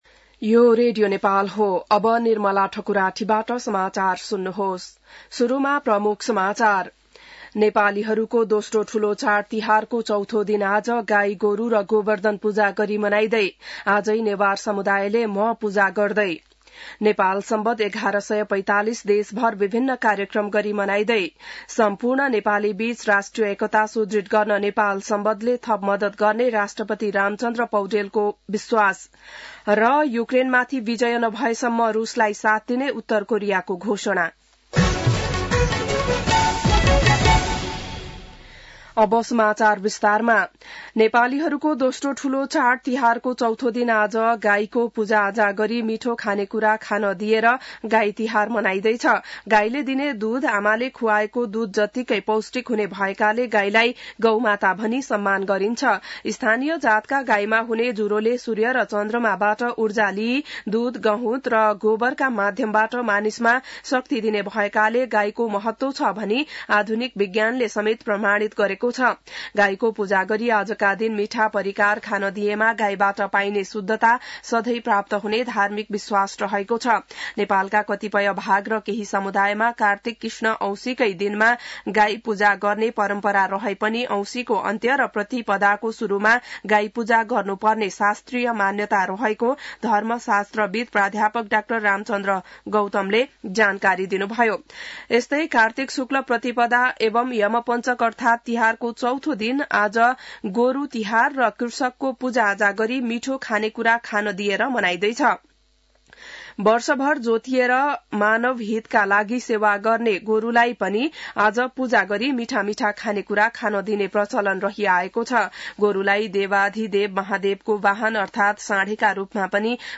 बिहान ९ बजेको नेपाली समाचार : १८ कार्तिक , २०८१